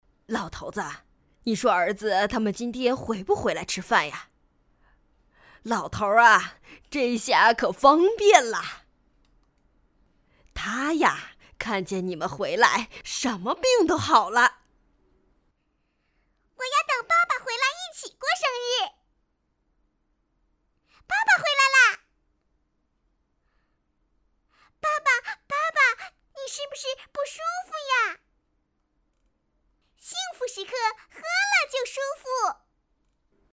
女国19_动画_老人_百方罗汉奶奶女孩儿.mp3